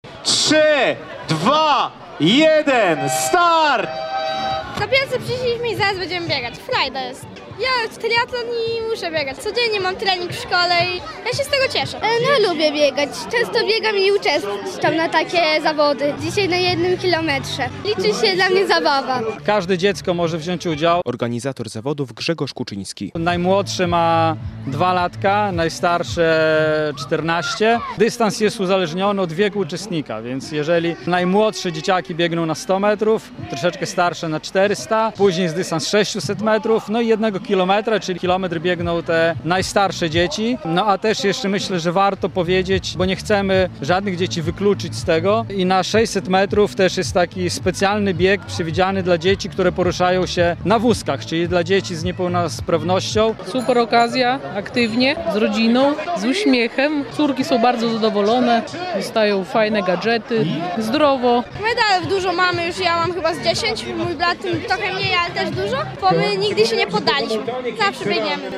Pierwsza część imprezy Białystok Biega, czyli start najmłodszych biegaczy - relacja